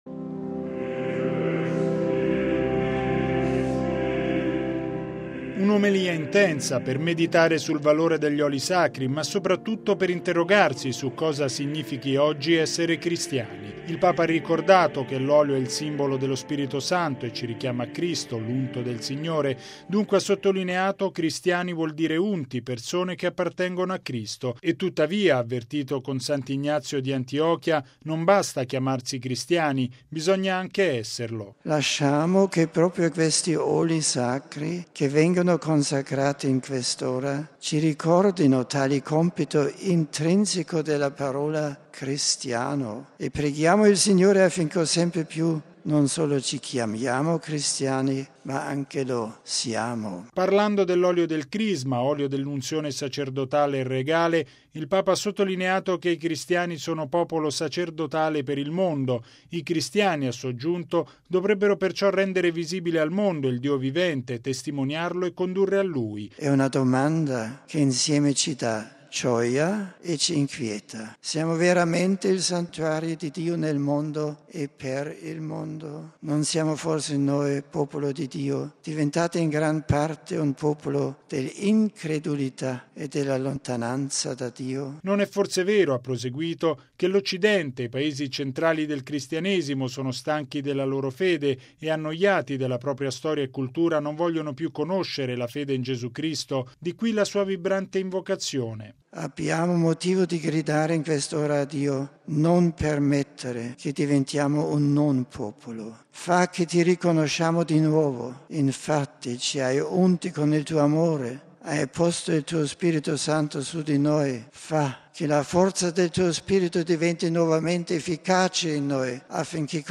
(Canti)